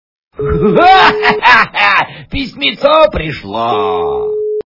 » Звуки » звуки для СМС » Звонок для СМС - Писемцо пришло
При прослушивании Звонок для СМС - Писемцо пришло качество понижено и присутствуют гудки.